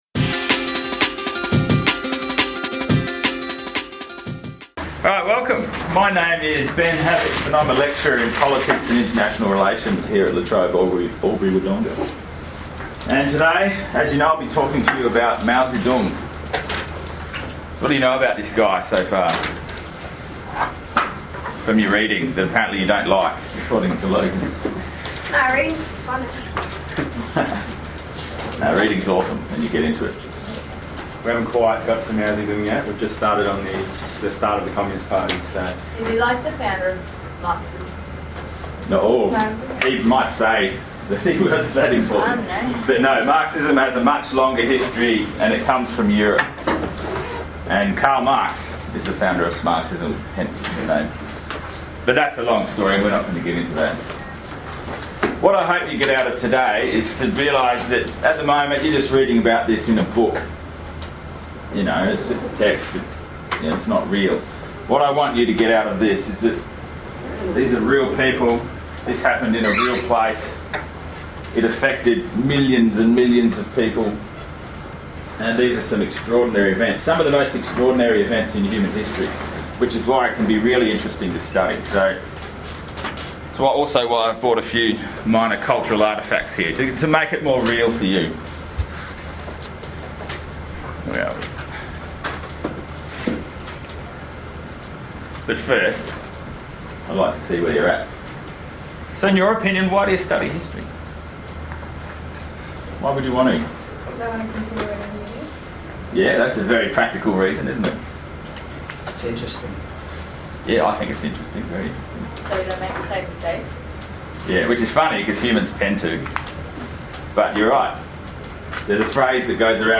Mao Zedong and the Chinese Communist Party: Presentation to Victory Lutheran College VCE History Class